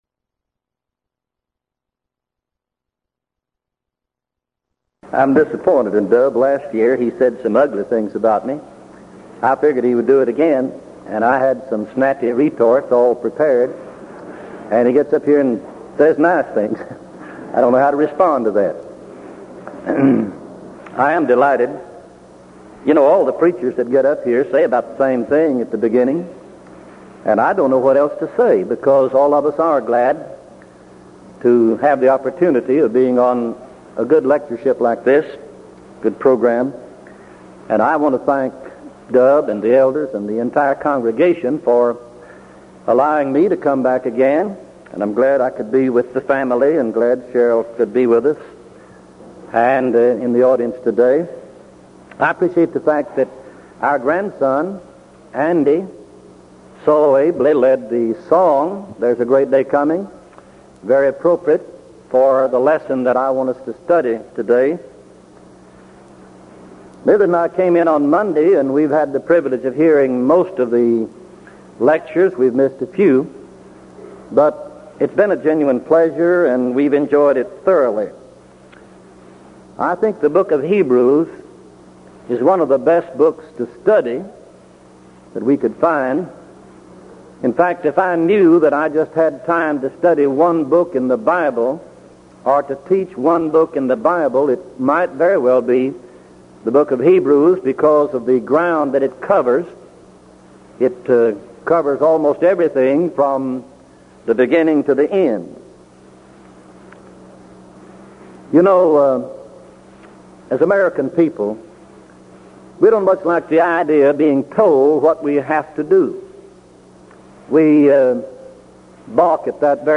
Event: 1983 Denton Lectures Theme/Title: Studies in Hebrews